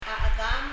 発音
dirrek　　　[ðirek]　　　　　　　　　・・・もまた、　　also